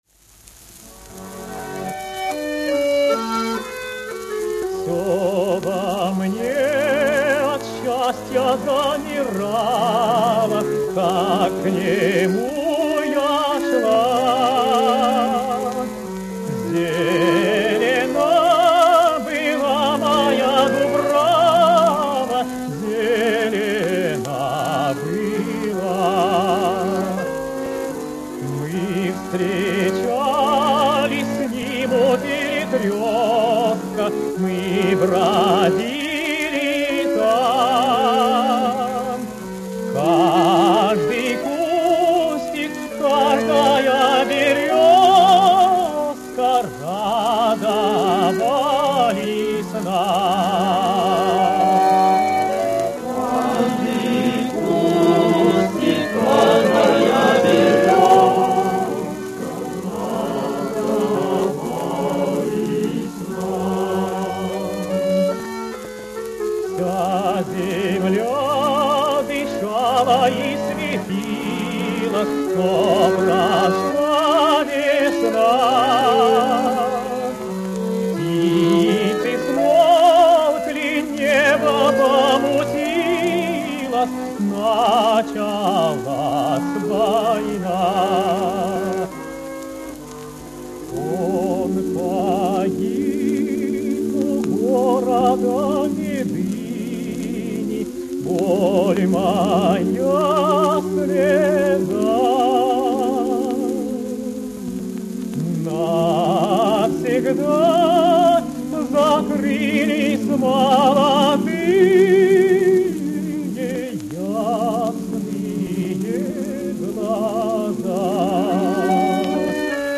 Вокальный октет и трио баянистов